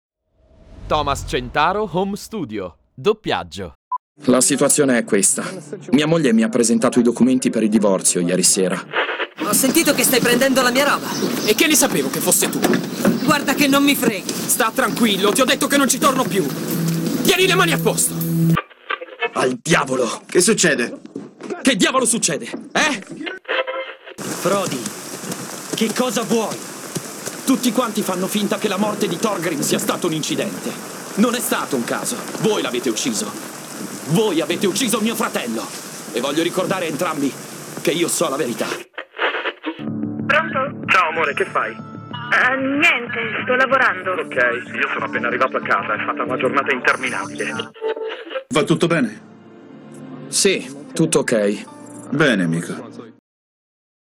ITALIAN MALE VOICE OVER ARTIST
I own the latest technology equipment wrapped in a soundproof environment for high quality professional recordings that guarantee a result in line with the prestige of the brand to be promoted.
DUBBING